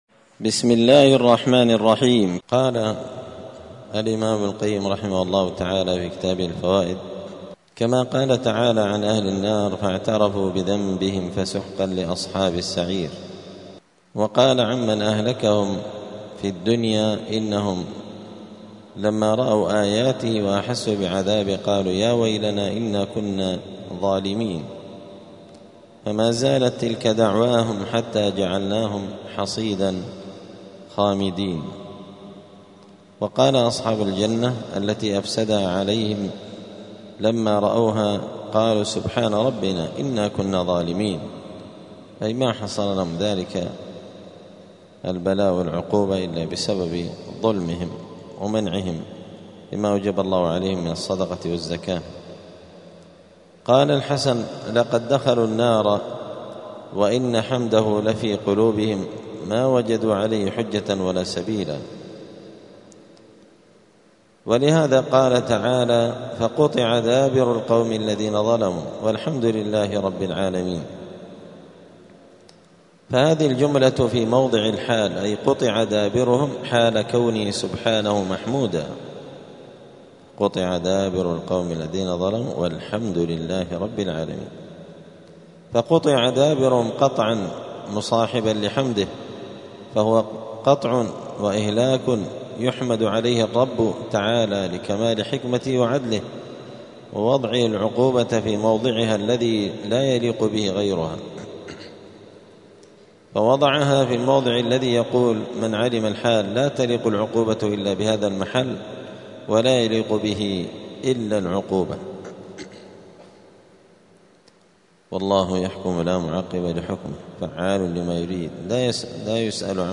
*الدرس الثالث والتسعون (93) {فصل ﻋﻈﻴﻢ اﻟﻨﻔﻊ}*